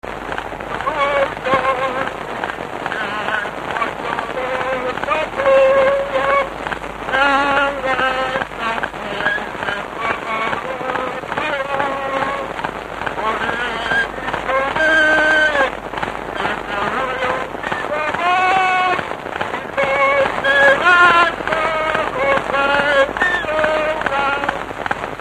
Felföld - Borsod vm. - Kisgyőr
Gyűjtő: Lajtha László
Stílus: 8. Újszerű kisambitusú dallamok
Kadencia: 1 (1) 2 1